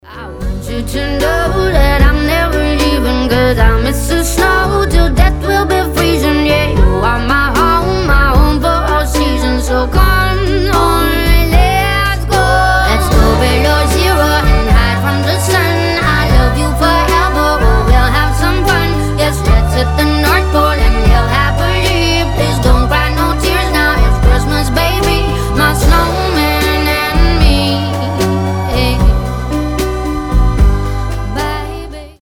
• Качество: 320, Stereo
Зимние